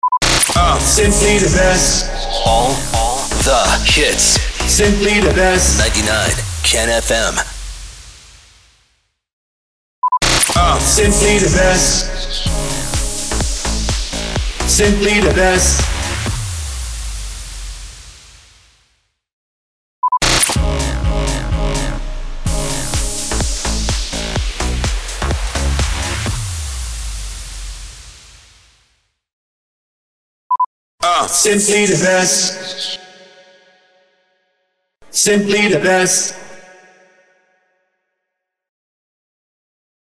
446 – SWEEPER – SIMPLY THE BEST
446-SWEEPER-SIMPLY-THE-BEST.mp3